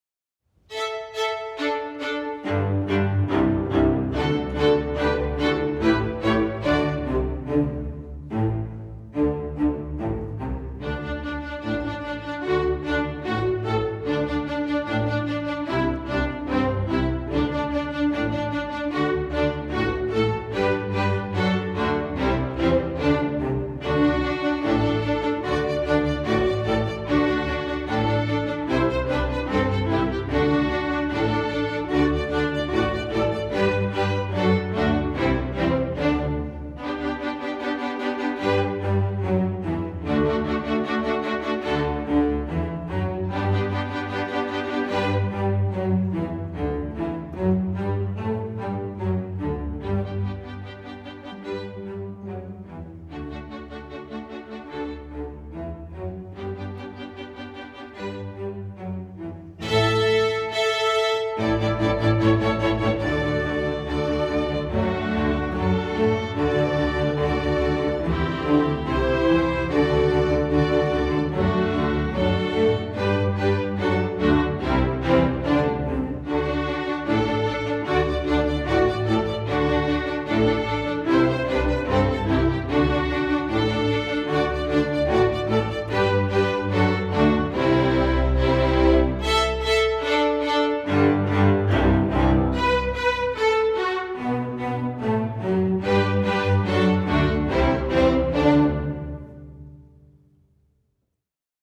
String Orchestra